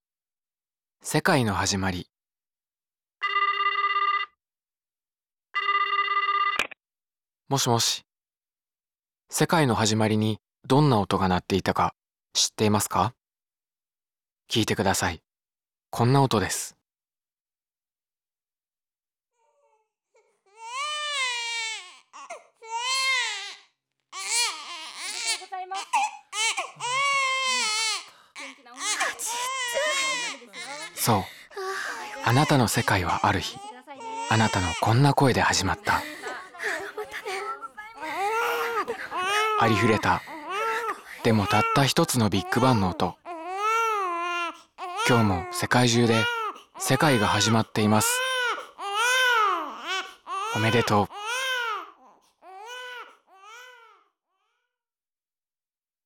音声サンプル
本展示では、さまざまな人のつながりのウェルビーイングに関する物語を音声で体験できるようにしています。